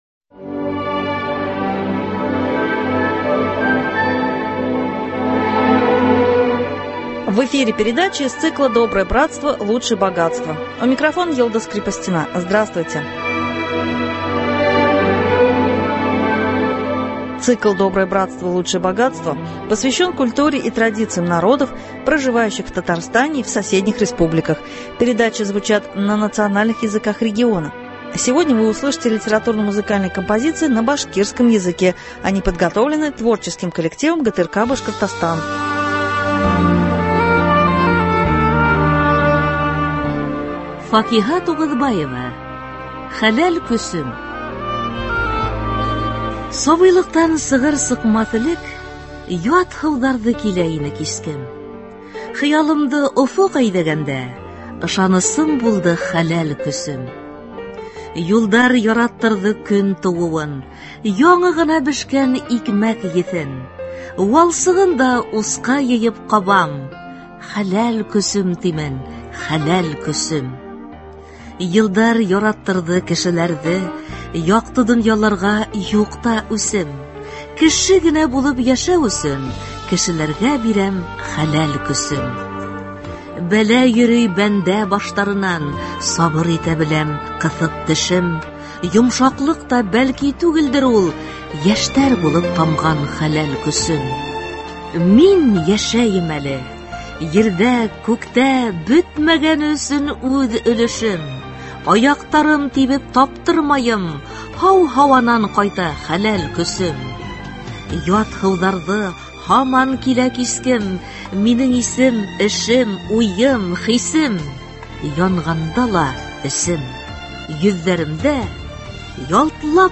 Литературно- музыкальная композиция на башкирском языке.